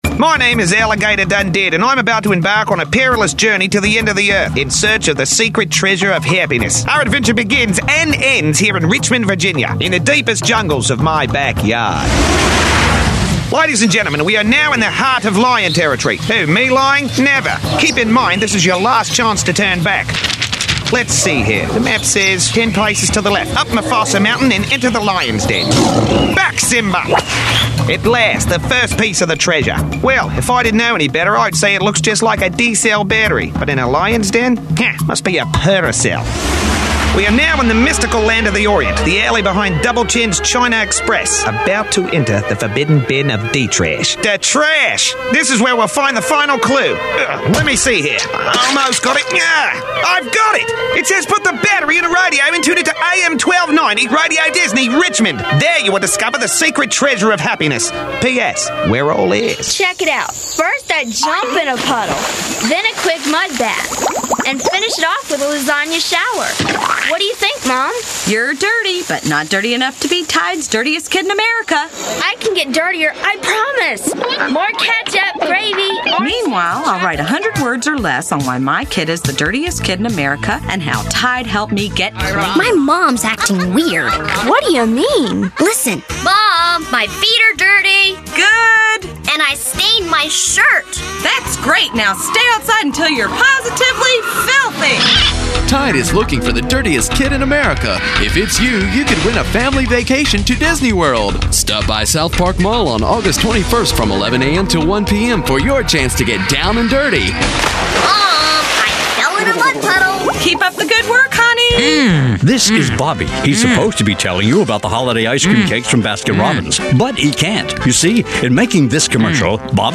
Last, but definitely not least, is a montage of spots written, produced and engineered by me.